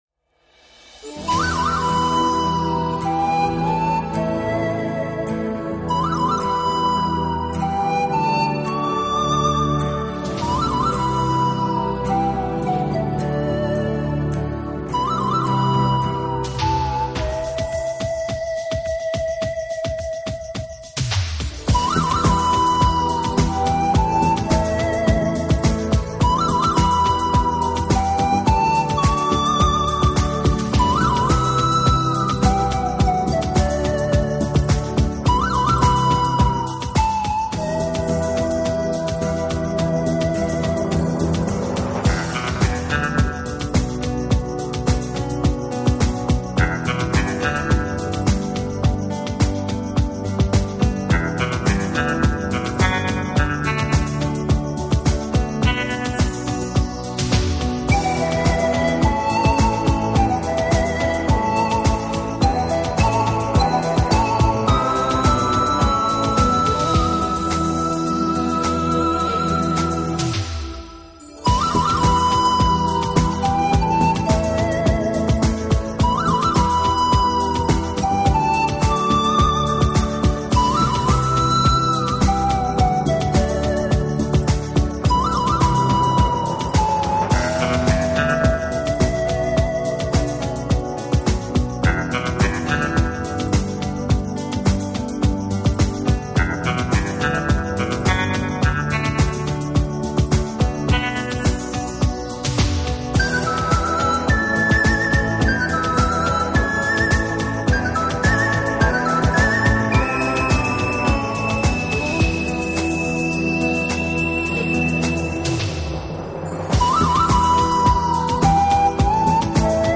Genre: Instrumental, Classical